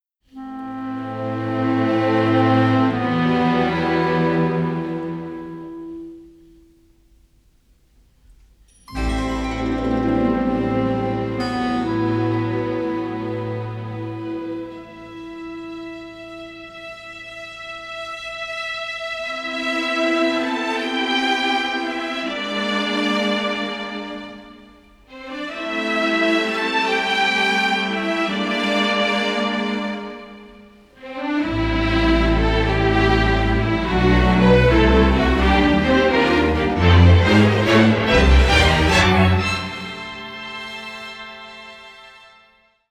tense suspense music